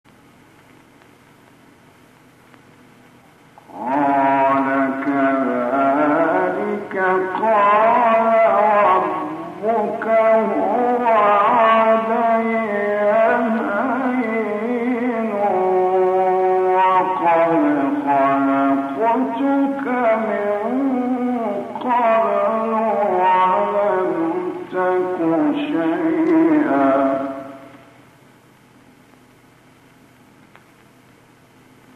گروه شبکه اجتماعی: نغمات صوتی از قاریان ممتاز مصری ارائه می‌شود.